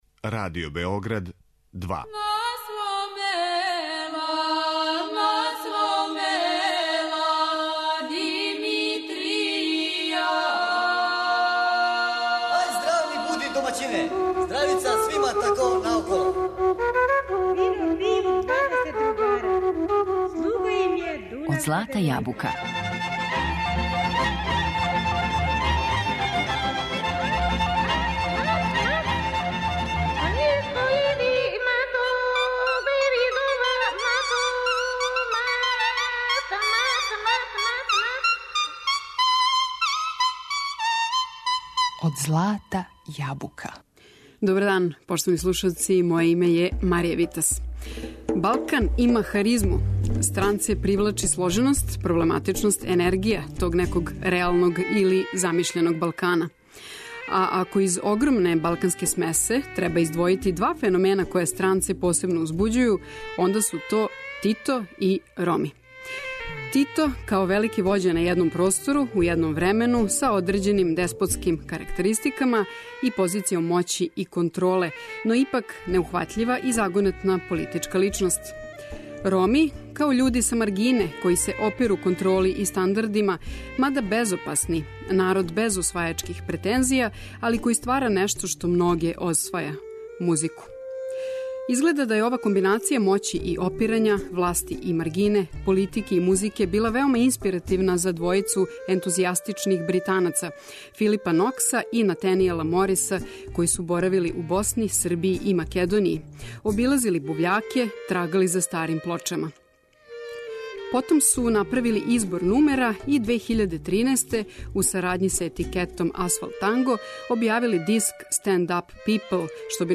Ромска музика Балкана